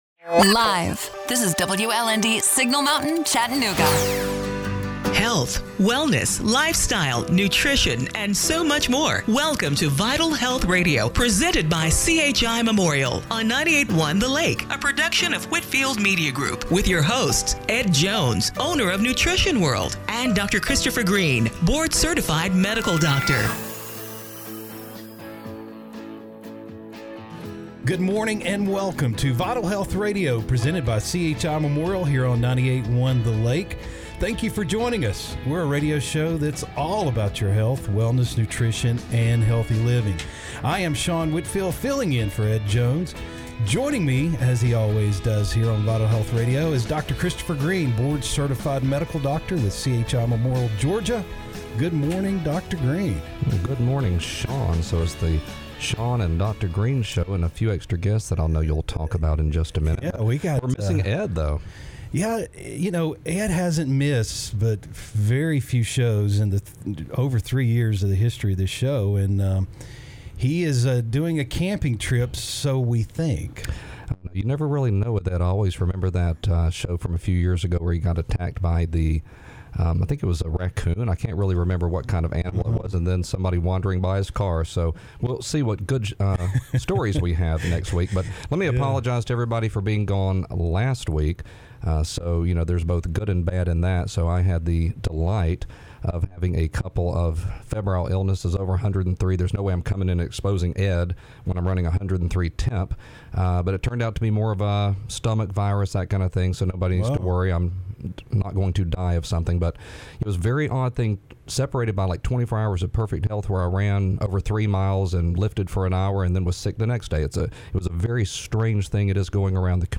April 25, 2021 – Radio Show - Vital Health Radio